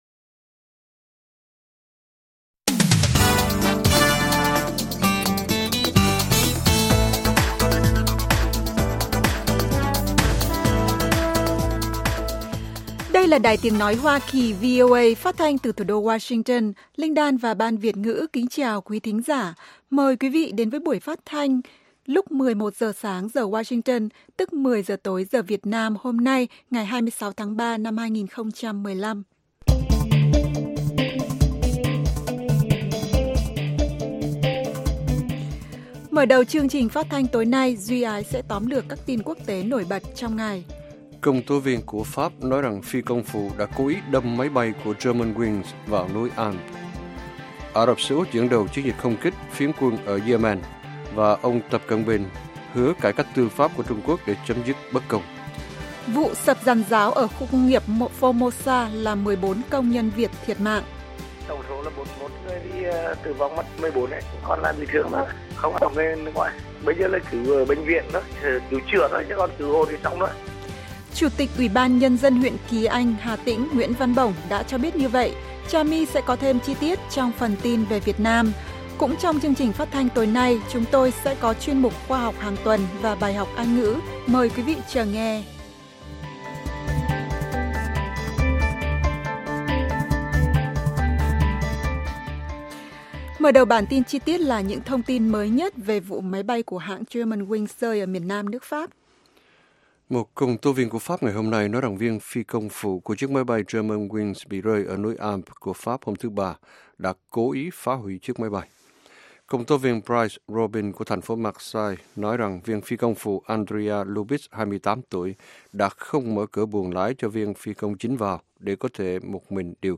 Các bài phỏng vấn, tường trình của các phóng viên VOA về các vấn đề liên quan đến Việt Nam và quốc tế, và các bài học tiếng Anh.